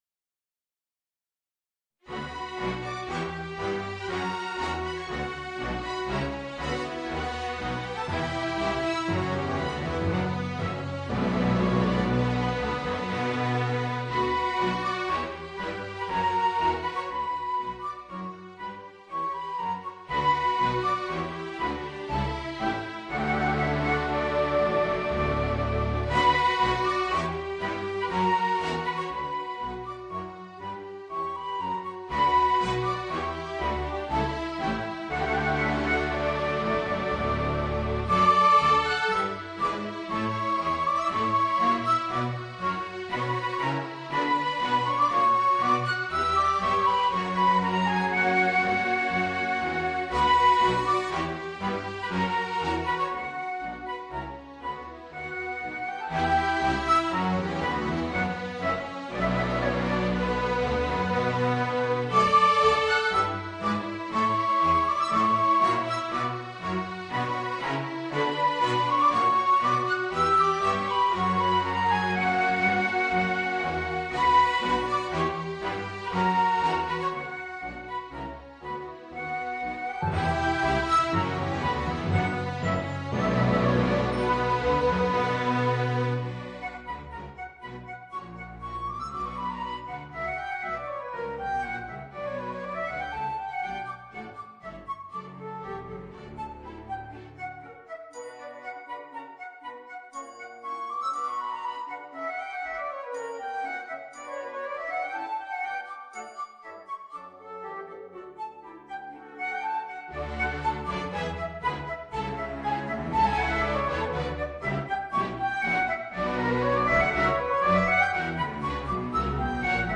Voicing: Clarinet and Orchestra